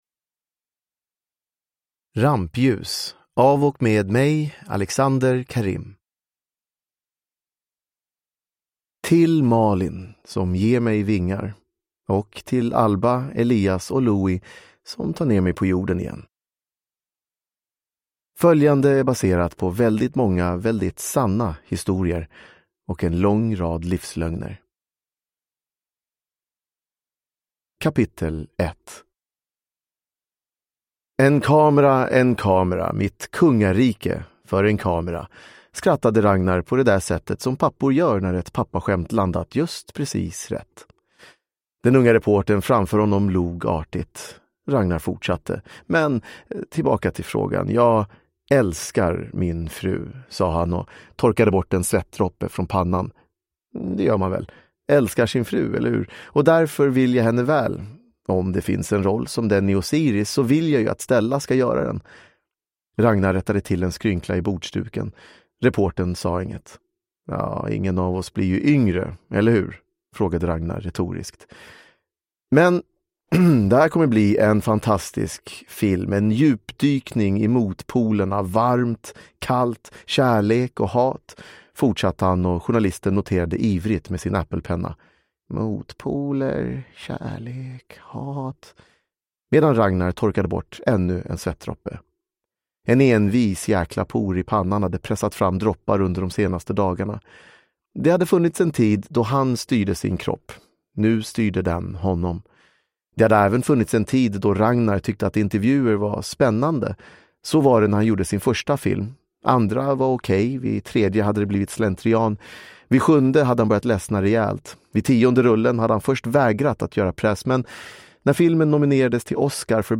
Rampljus – Ljudbok
Uppläsare: Alexander Karim